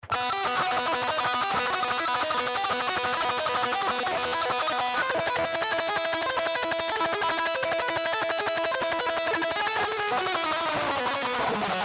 Пример звука Revolver (distortion)
Записано на гитаре Fender Squier
distortion1.mp3